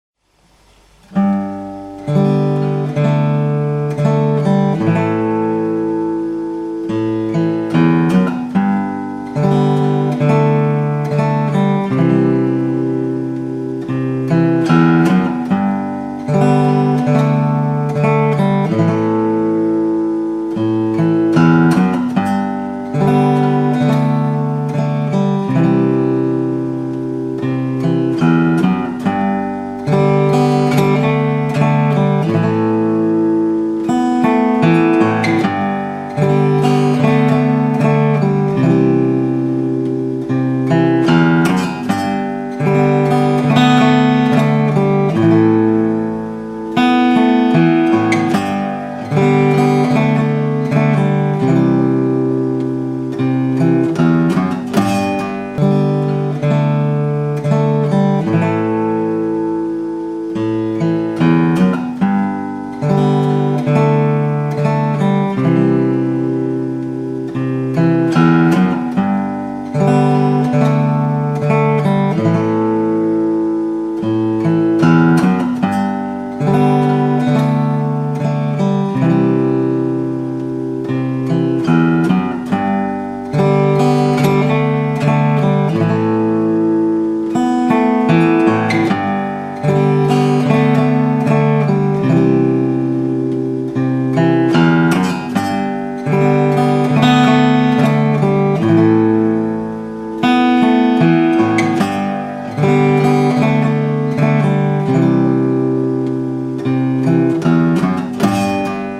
Audio Clip from the Tutorial
Standard Tuning - 4/4 Time